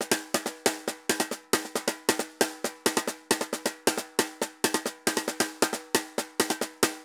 Tambor_Merengue 136-2.wav